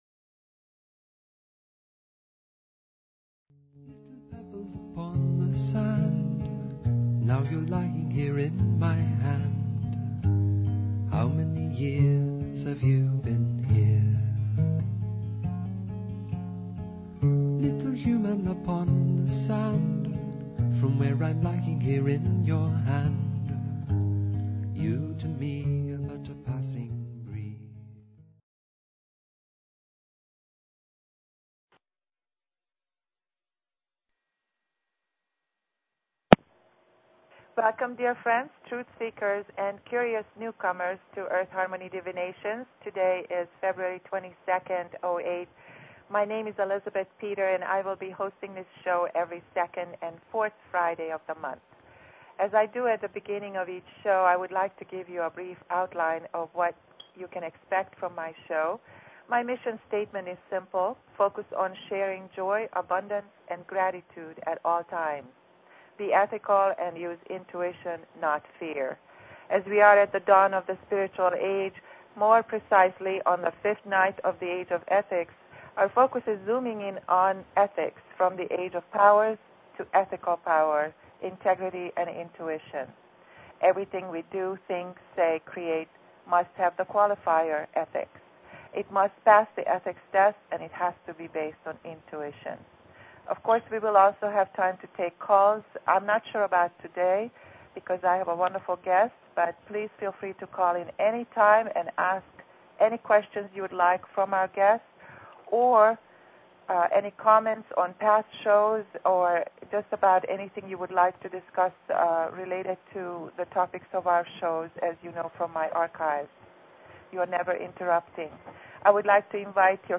Talk Show Episode, Audio Podcast, Earth_Harmony_Divinations and Courtesy of BBS Radio on , show guests , about , categorized as